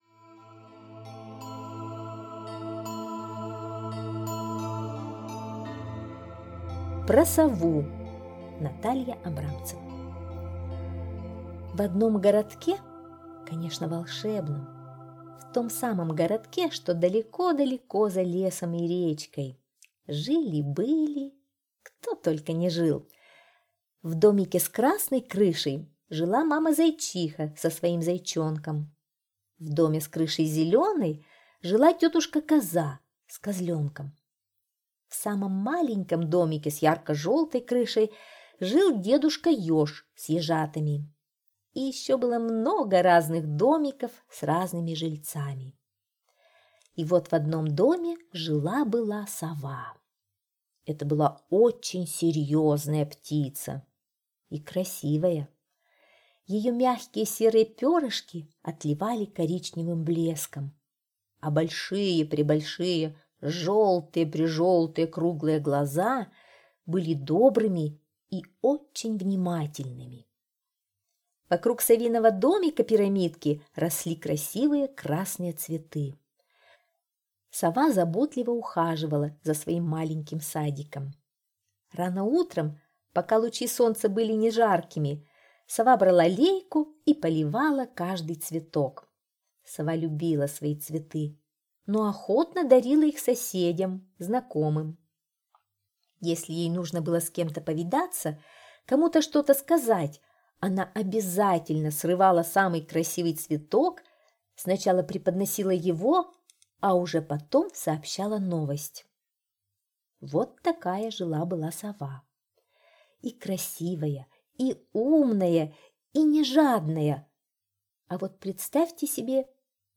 Про сову - аудиосказка Абрамцевой Н.К. Сказка про жителей лесного городка.